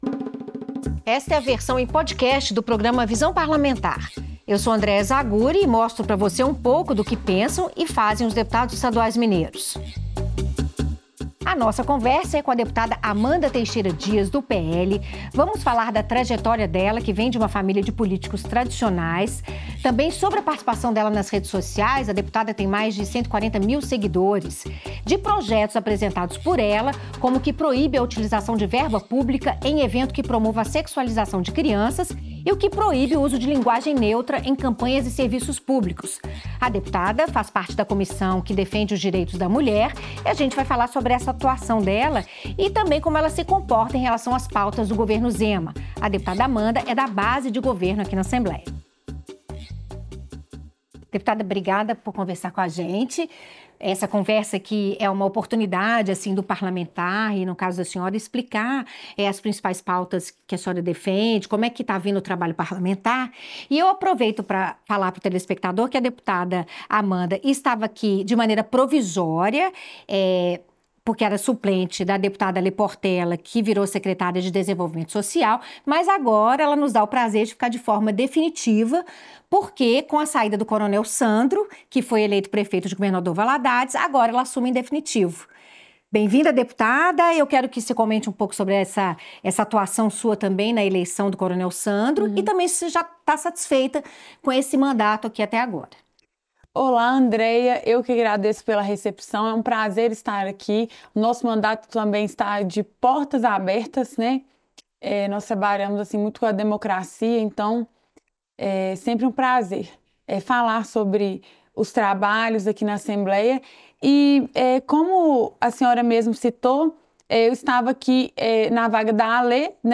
Na entrevista, Amanda Teixeira Dias comenta sobre o “rejuvenescimento” da direita no Brasil e destaca o papel de liderança exercido pelo ex-presidente Jair Bolsonaro (PL).